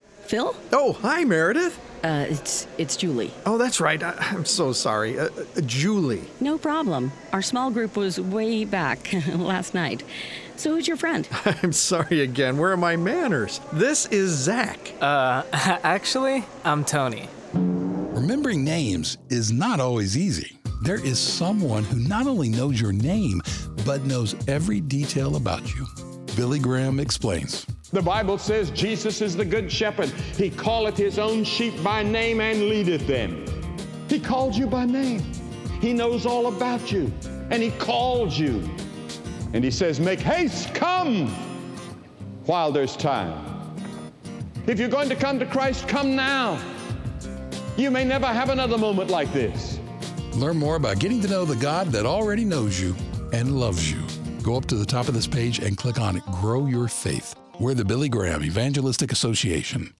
God not only knows your name, but knows every detail about you. Billy Graham explains in this 60-second sermon.